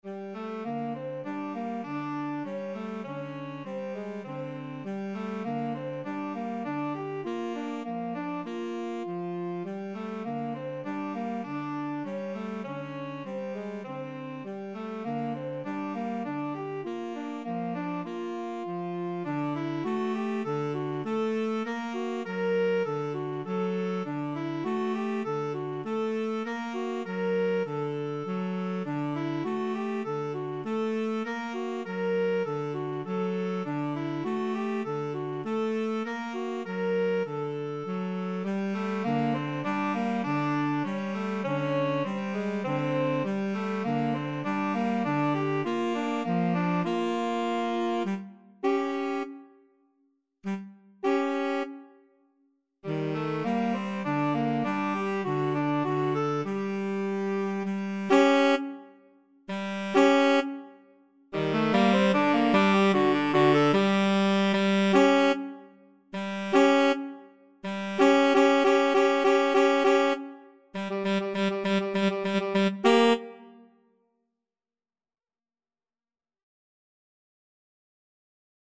Tenor Sax 1Tenor Sax 2
Classical (View more Classical Tenor Sax Duet Music)